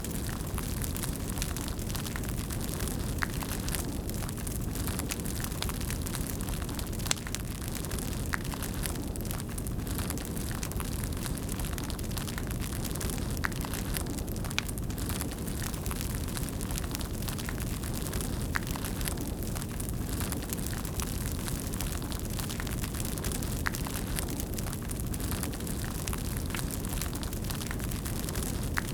Fantasy_Ambience_Sounds_Pack
Fire_Loop_01.wav